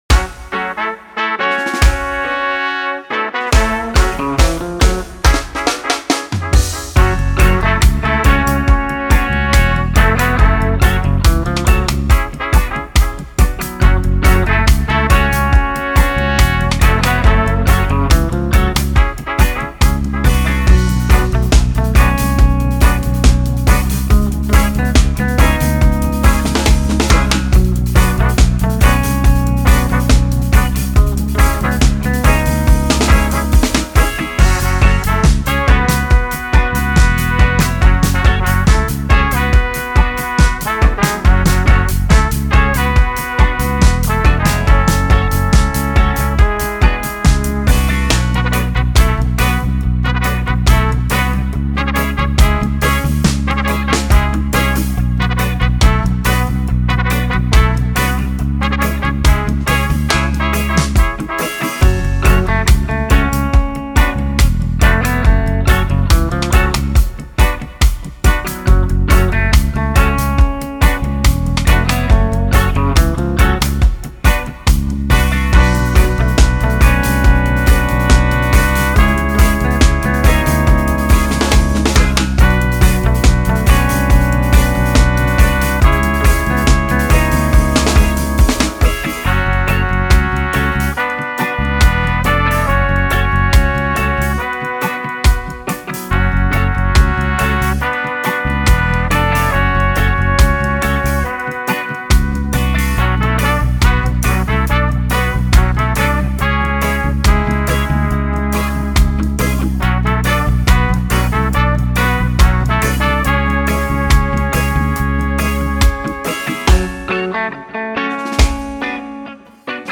Genre:Reggae
重要：デモで聴こえるその他の楽器はすべてプレビュー目的であり、このパックはホーンのためだけのものです。
Live Performed & Played